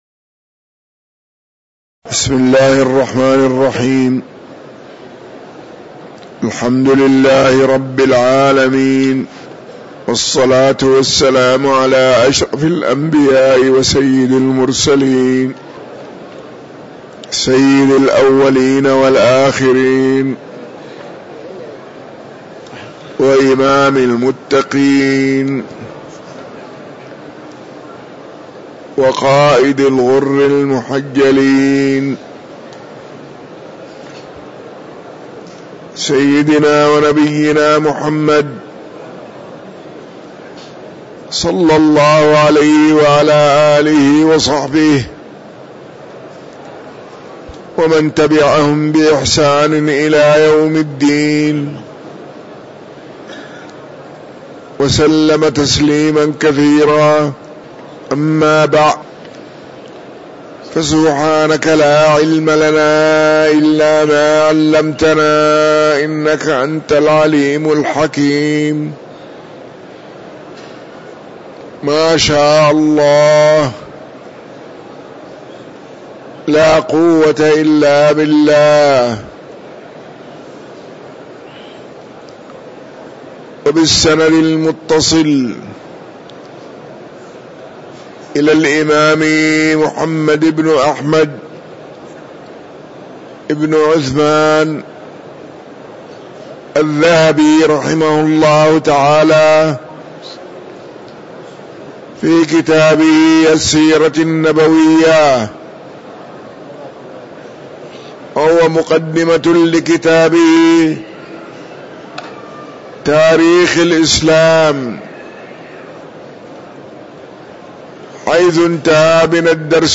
تاريخ النشر ٢ ربيع الأول ١٤٤٥ المكان: المسجد النبوي الشيخ